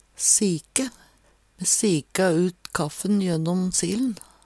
DIALEKTORD PÅ NORMERT NORSK sike skille varsamt væske frå noko fast Infinitiv Presens Preteritum Perfektum sike sik sika sika Eksempel på bruk Ho sika ut kaffen jønnom sil`n. Hør på dette ordet Ordklasse: Verb Attende til søk